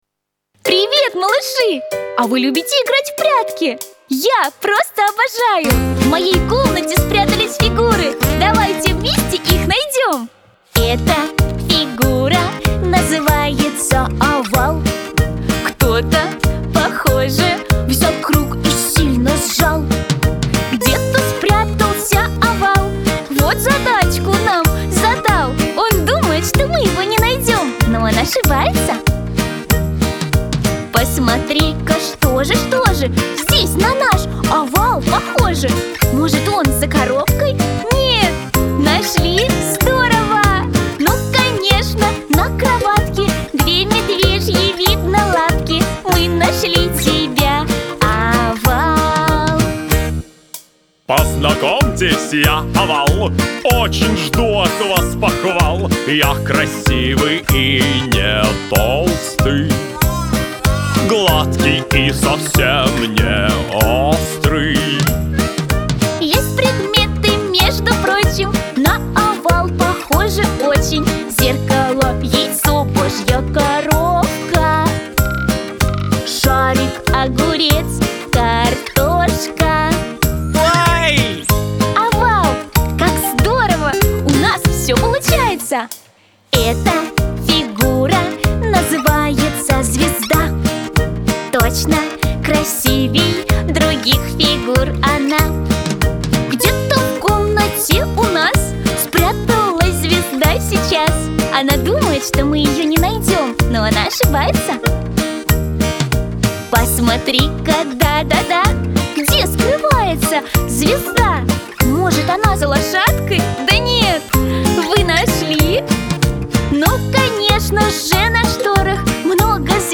Веселая музыка